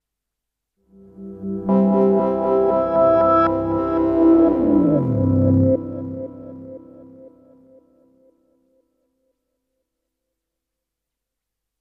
Reversed comp original with chord